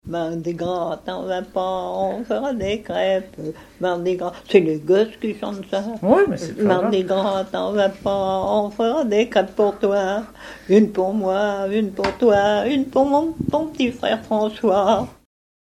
Mémoires et Patrimoines vivants - RaddO est une base de données d'archives iconographiques et sonores.
Chants brefs - Mardi-Gras
enfantine : comptine
Catégorie Pièce musicale inédite